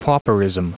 Prononciation du mot pauperism en anglais (fichier audio)
Prononciation du mot : pauperism